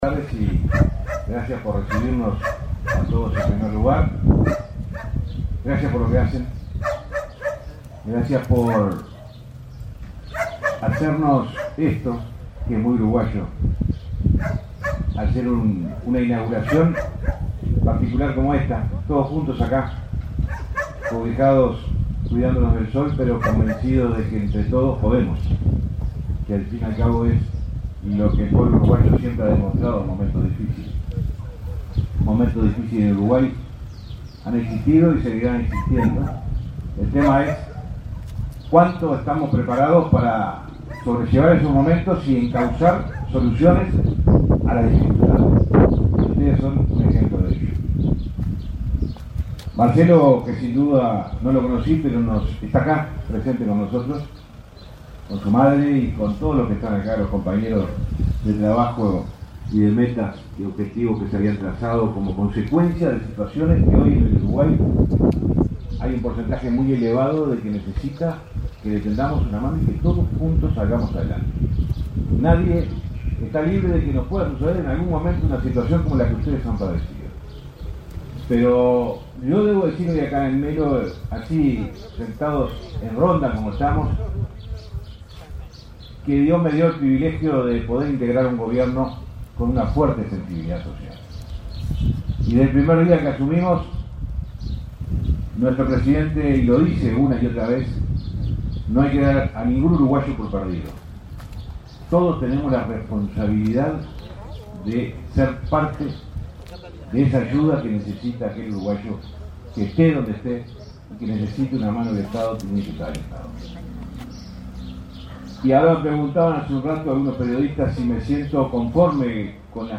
Palabras del ministro de Transporte y Obras Públicas, José Luis Falero
Palabras del ministro de Transporte y Obras Públicas, José Luis Falero 04/12/2024 Compartir Facebook X Copiar enlace WhatsApp LinkedIn El Ministerio de Transporte y Obras Públicas participó, este 4 de diciembre, en la inauguración del centro de atención a la discapacidad UDI 3 de Diciembre, en Melo, Cerro Largo. En el evento disertó el ministro José Luis Falero.